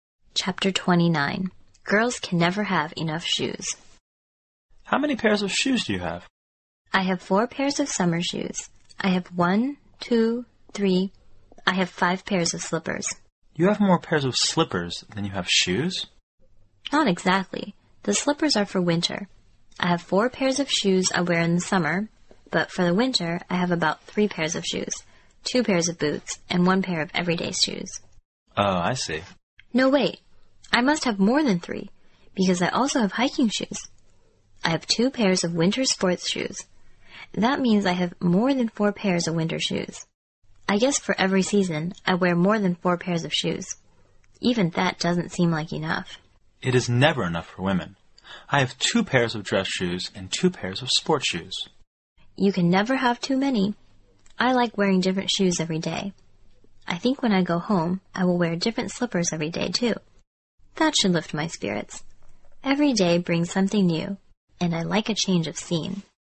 摄取生活原生态，摒弃假性交际，原汁原味的语言素材，习得口语的最佳语境。